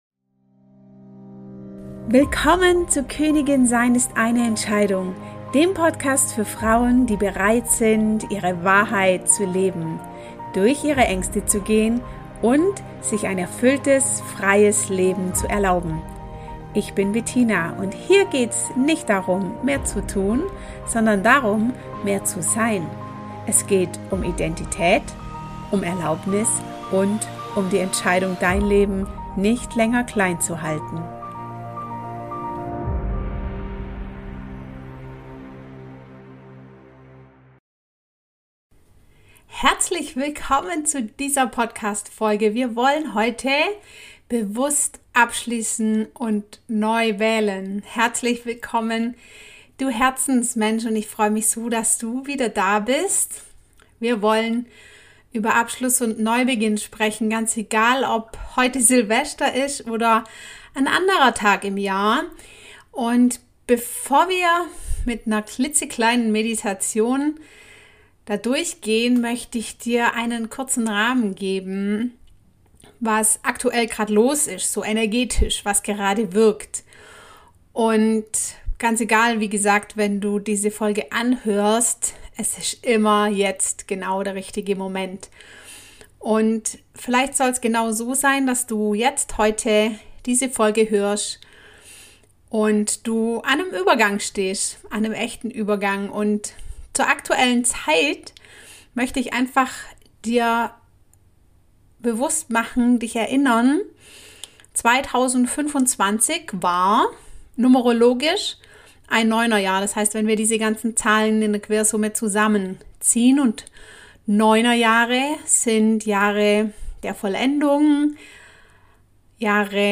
Gemeinsam gehen wir durch eine kurze Meditation, spüren nach, was gehen darf, und öffnen uns für das Neue mit Vertrauen und Mut.